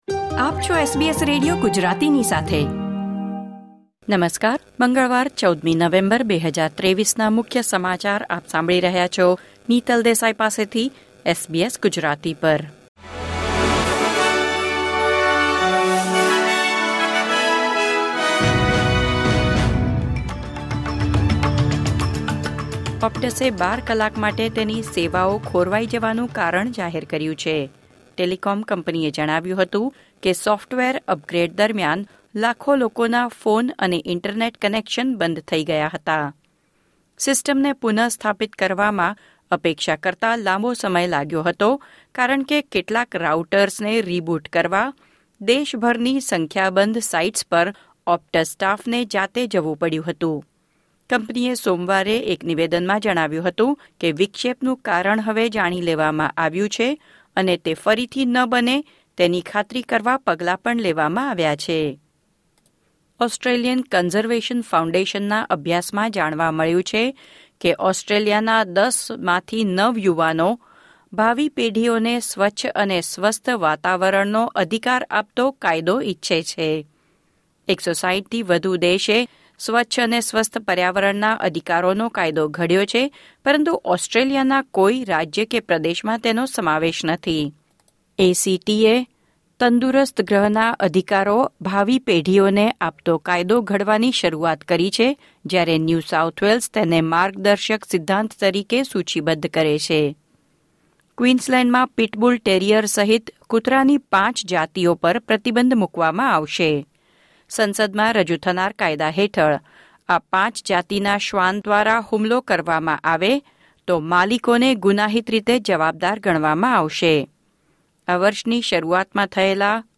SBS Gujarati News Bulletin 14 November 2023